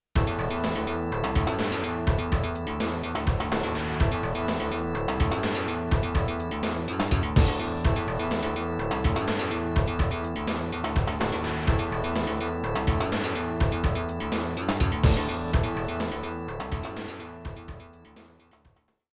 Player select theme